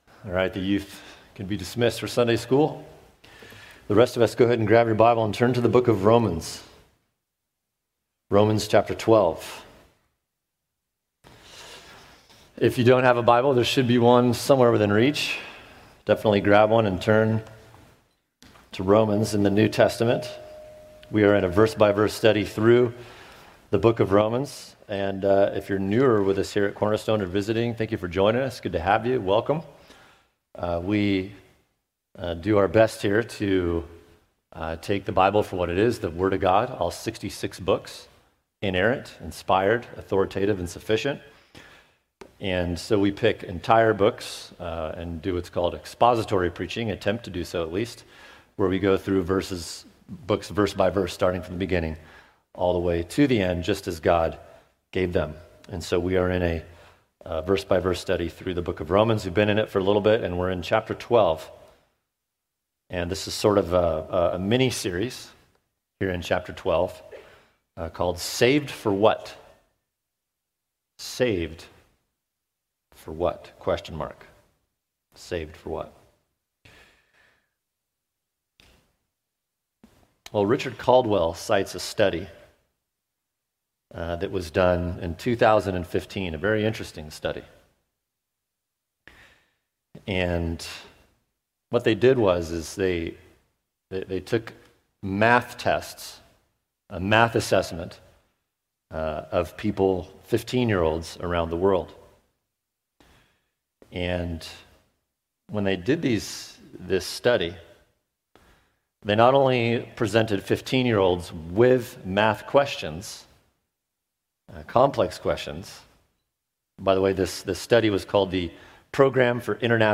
[sermon] Saved for Humility Romans 12:3 | Cornerstone Church - Jackson Hole